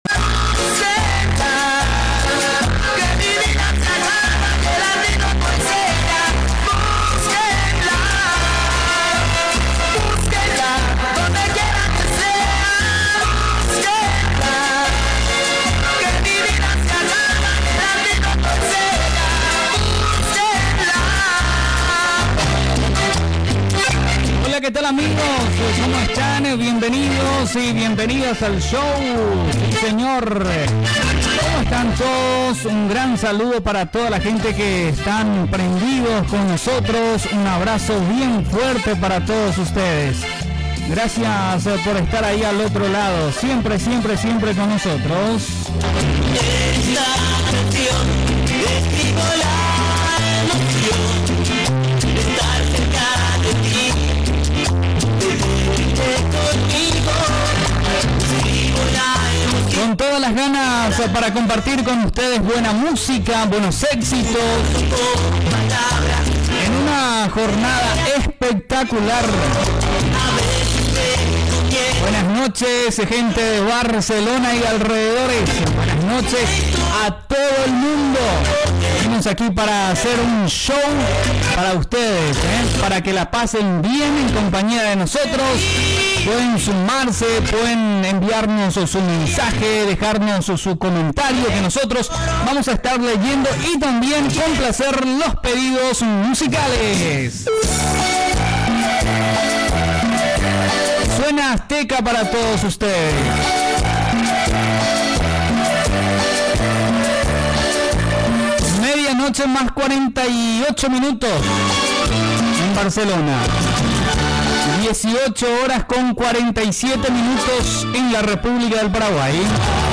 Presentació, hora, identificació de la ràdio, telèfon de contacte
Entreteniment